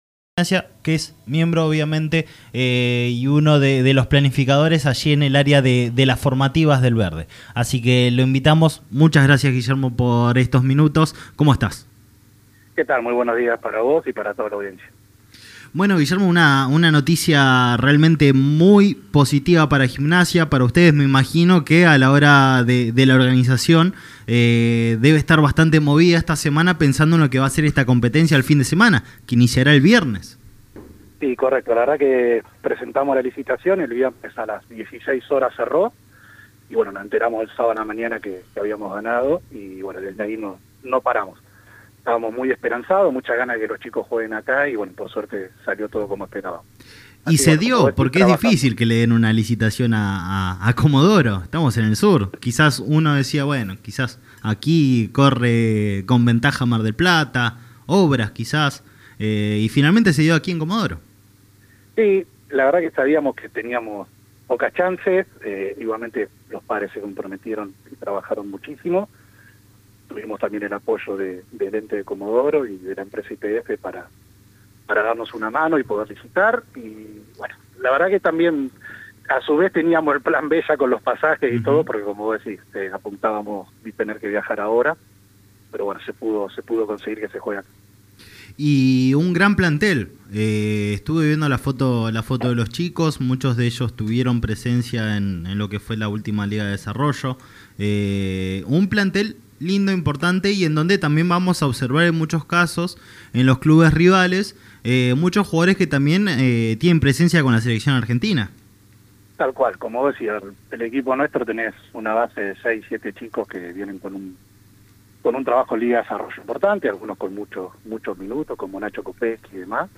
En diálogo con Radio del Sur 97.1